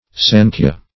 sankhya - definition of sankhya - synonyms, pronunciation, spelling from Free Dictionary
Sankhya \Sankh"ya\, n.